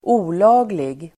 Ladda ner uttalet
Uttal: [²'o:la:glig]